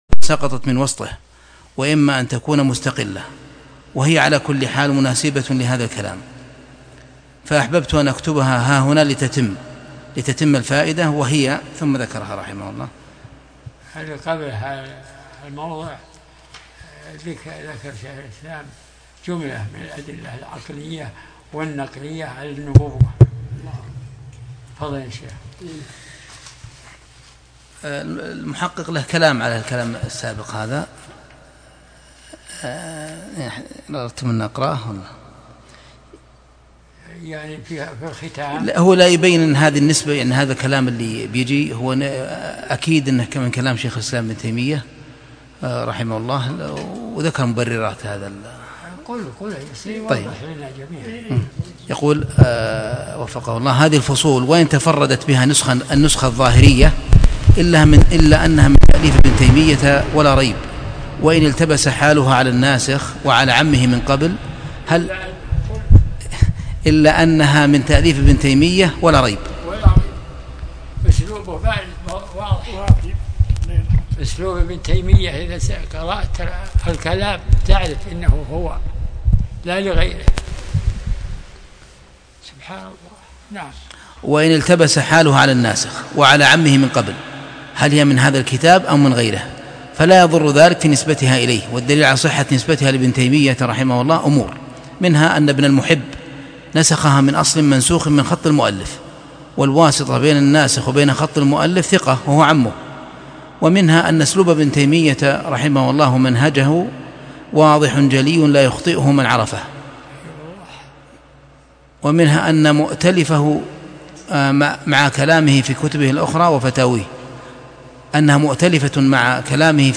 درس الأحد 51